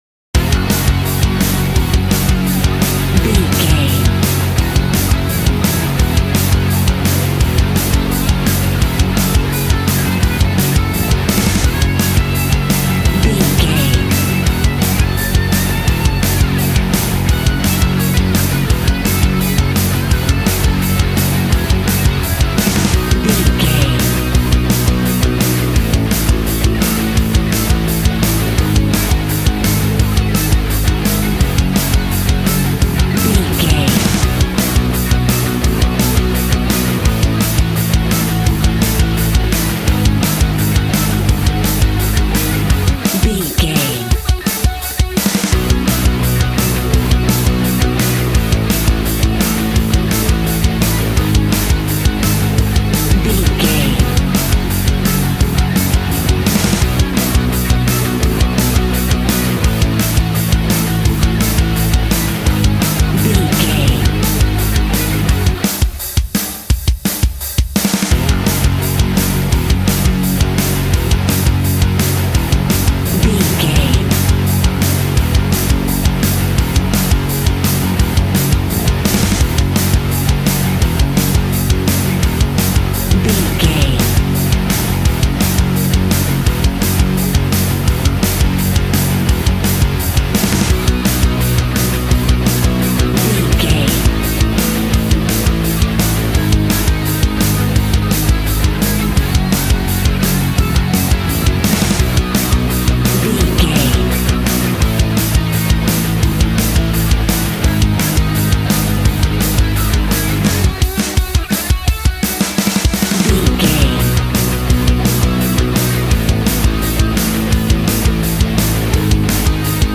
Aeolian/Minor
G♭
angry
powerful
aggressive
electric guitar
drums
bass guitar